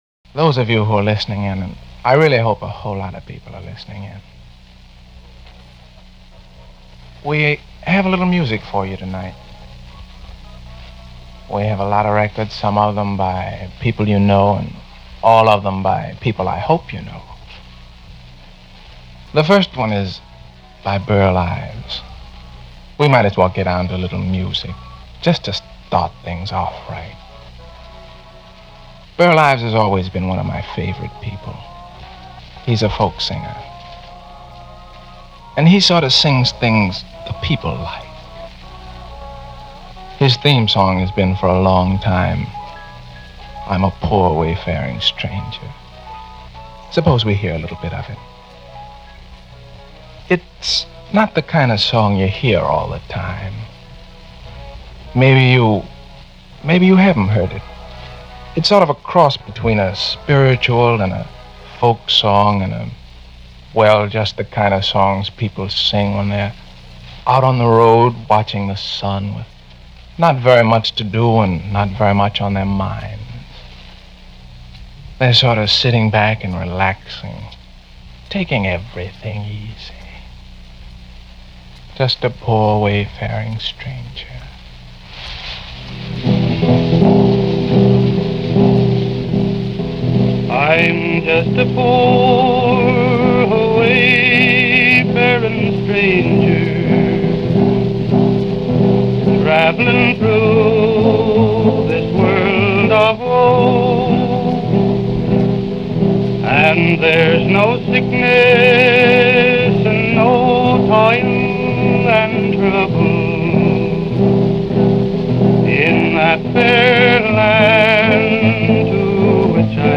If anything, the music and the announcers could lull you to sleep within minutes, unless you were a dyed-in-the-wool insomniac.
I have run earlier episodes of this program and the format is the same; calm music, soothing voices – homespun philosophy, only with different announcers. The intended effect is the same; falling asleep in five minutes or less.